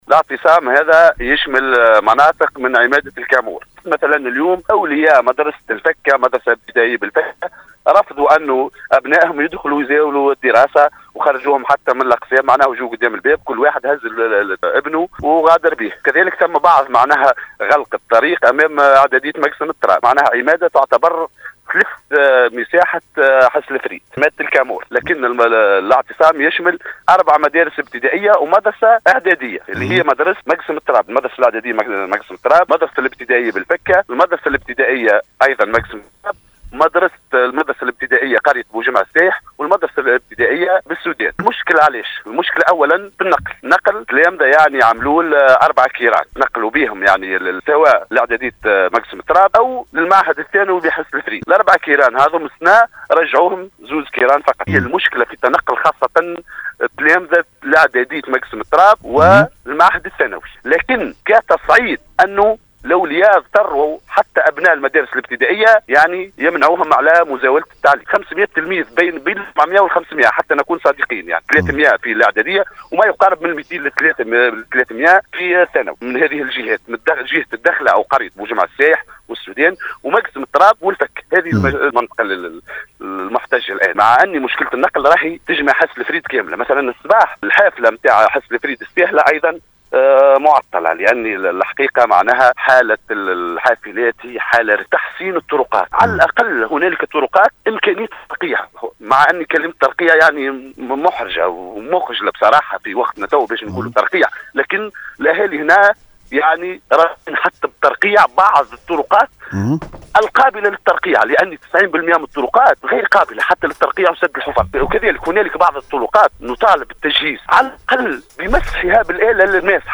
تصريح لأحد المحتجين